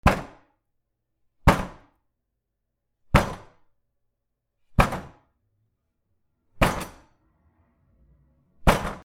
台車を殴る
/ H｜バトル・武器・破壊 / H-30 ｜打撃・衝撃・破壊　強_加工済 / 殴る